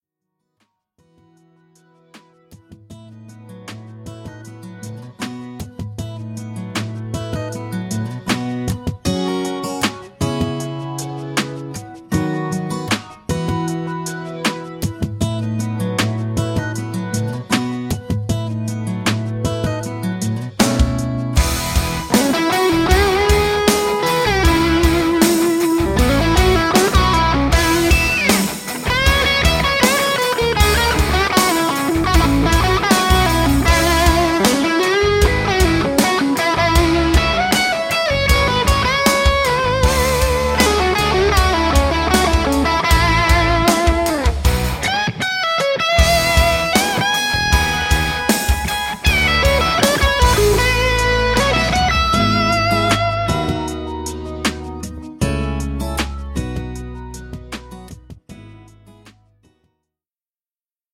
This is a Rocket recording and it sounds good but it doesn't have the same singing character that the Liverpool clip had.